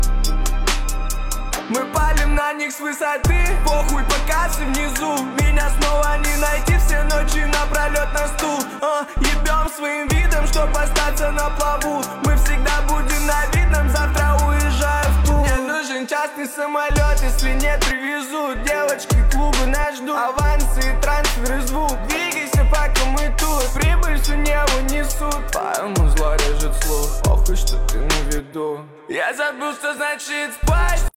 Жанр: Русские песни